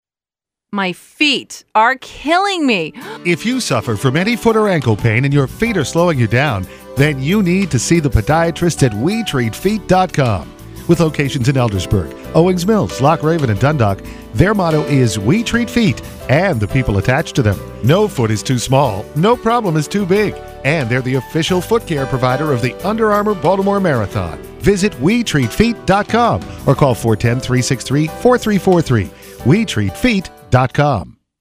We Treat Feet Radio Commercial